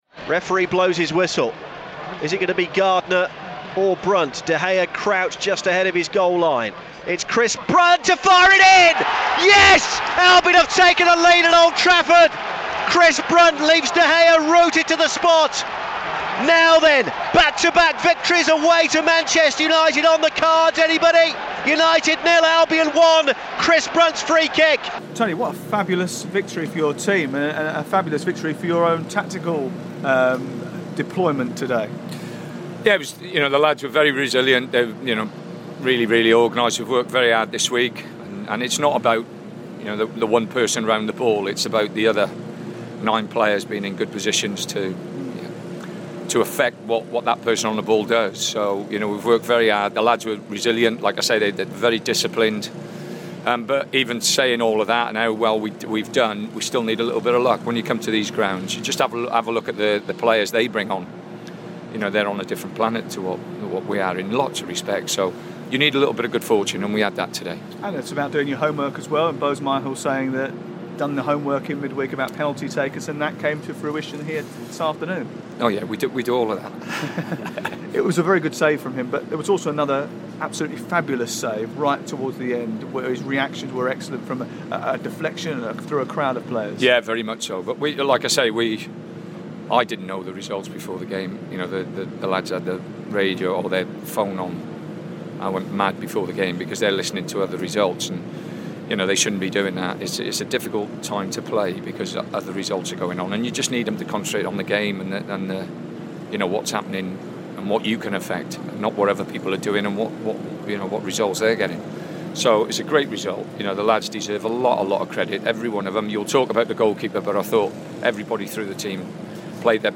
describes the action and Tony Pulis gives his thoughts.